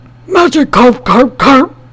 Audio / SE / Cries / MAGIKARP.mp3